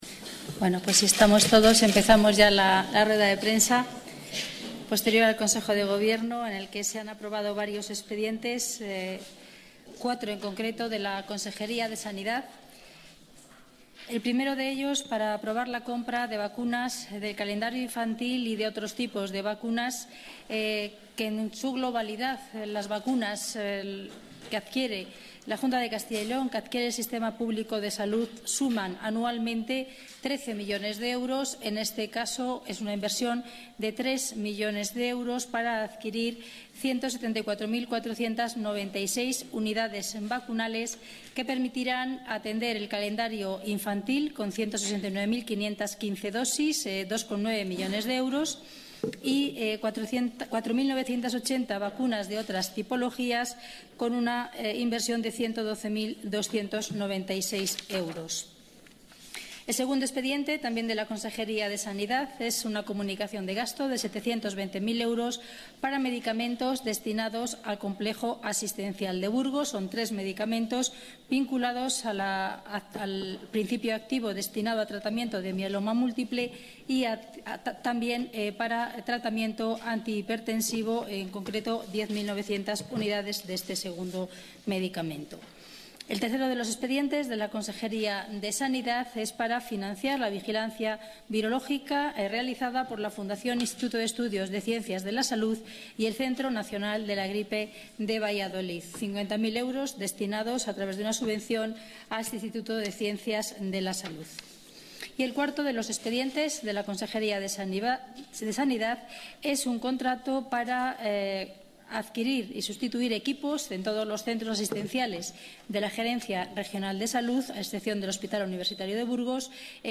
Rueda de prensa posterior al Consejo de Gobierno.